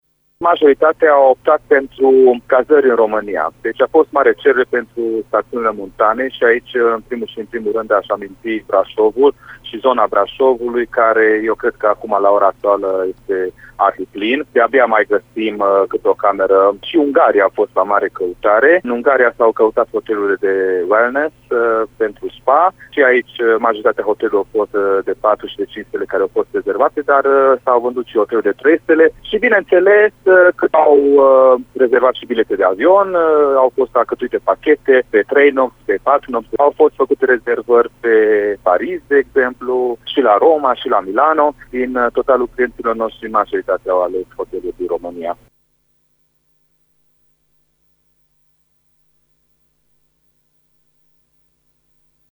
reprezentantul unei agenții de turism